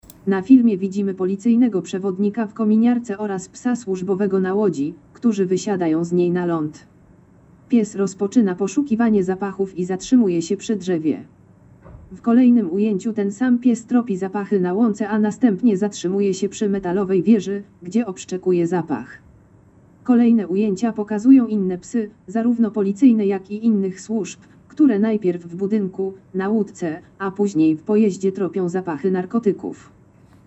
Nagranie audio Audiodeskrypcja filmu "Szkolenie policyjnych psów służbowych"